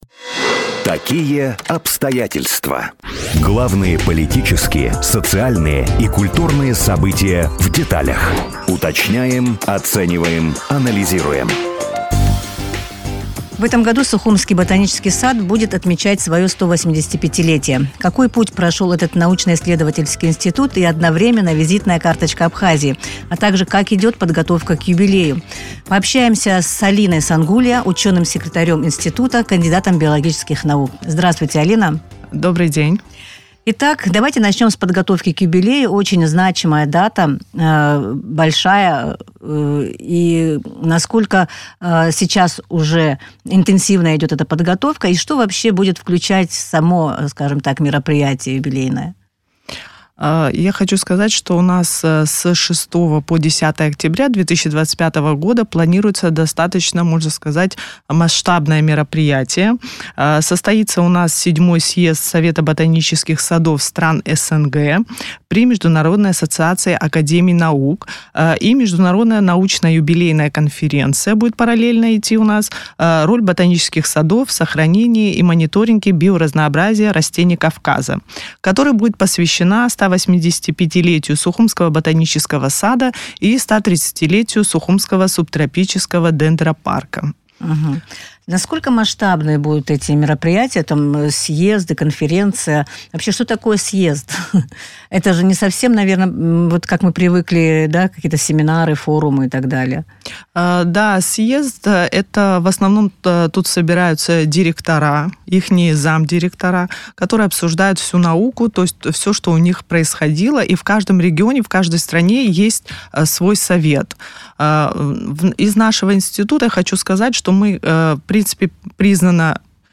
Какой путь прошел исследовательский институт и как идет подготовка к юбилею, в интервью...